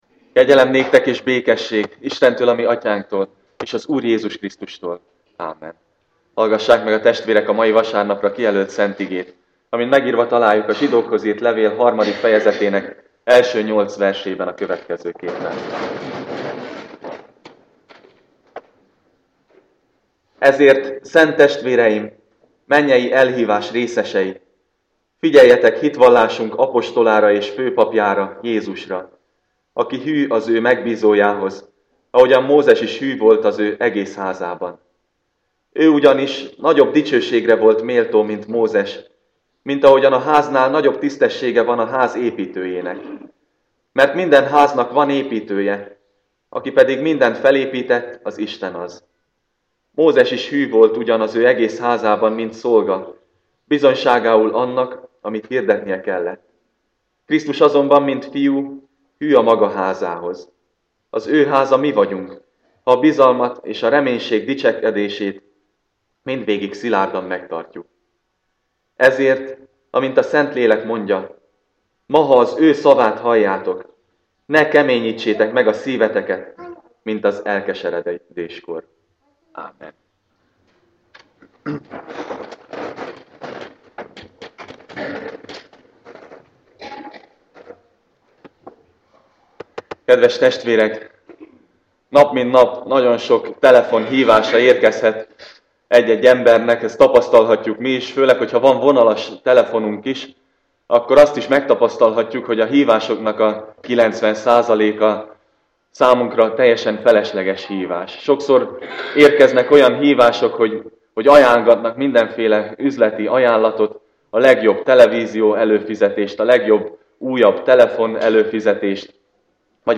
igehirdetése